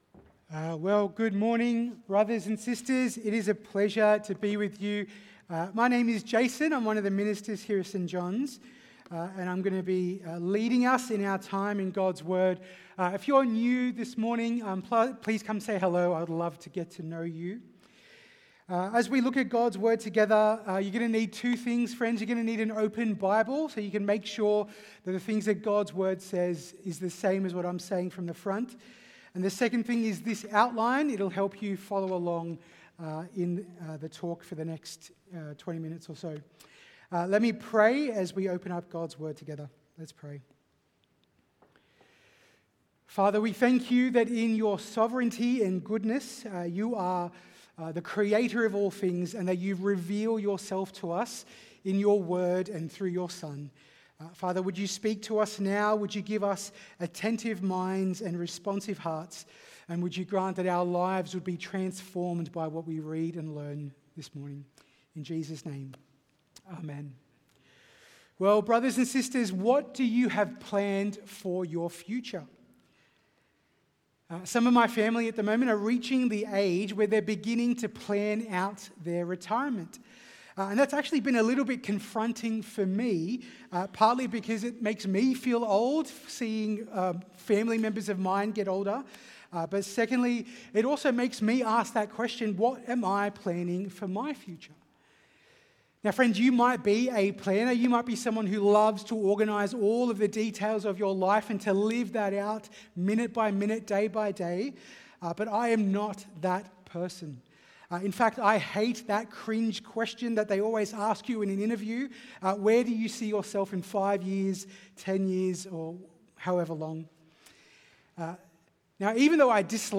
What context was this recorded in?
from St John’s Anglican Cathedral Parramatta